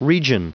Prononciation du mot : region
region.wav